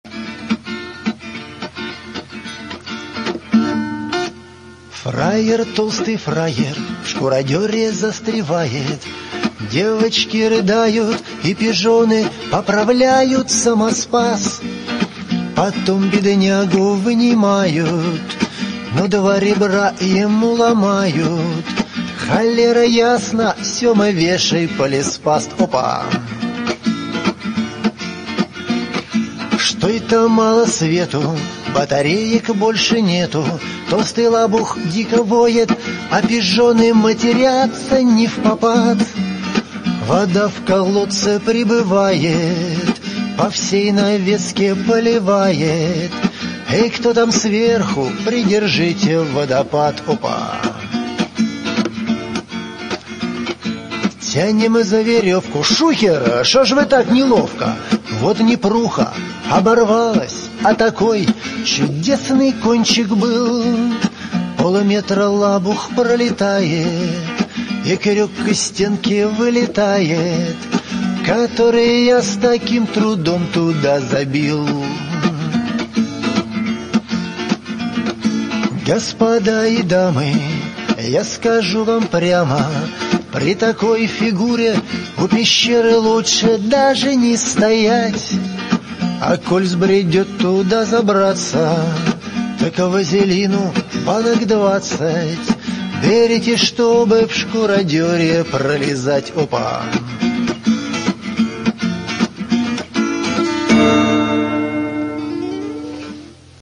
Шуточная песня